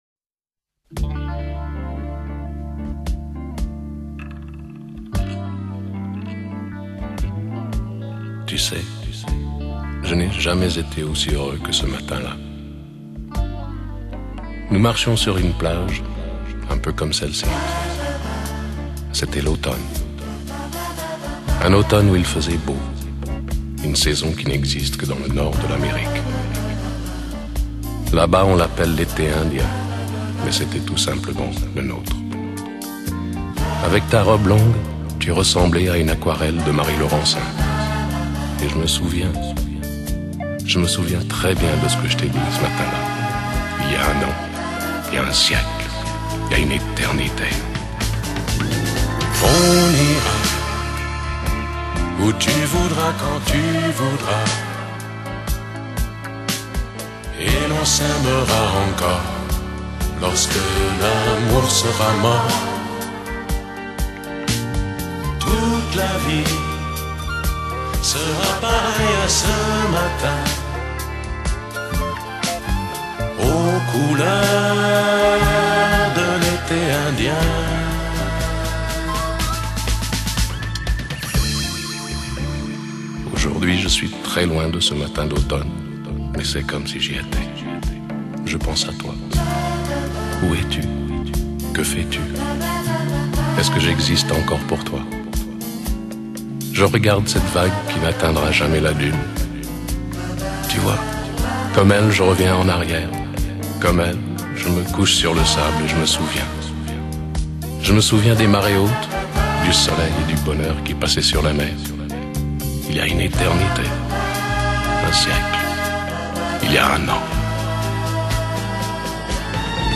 欧美浪漫经典